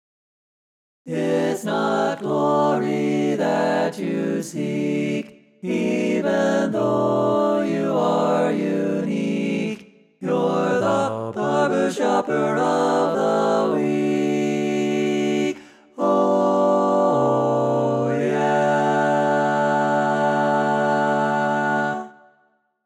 Key written in: D Major
Type: Barbershop